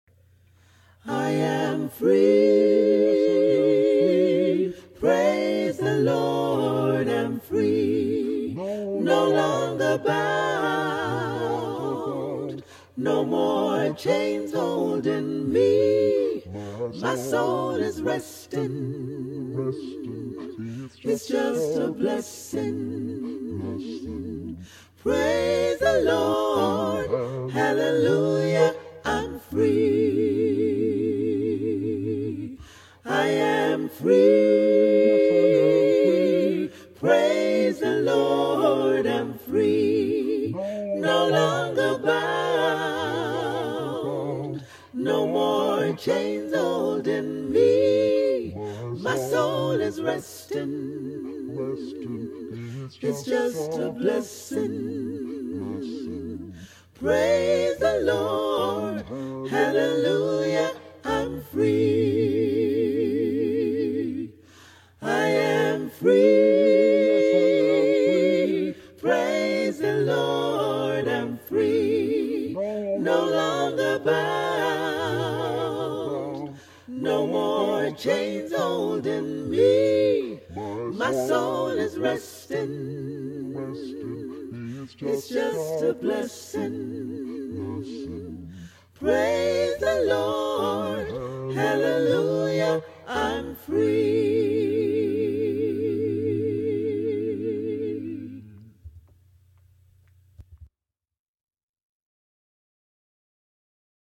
Genre: Choral.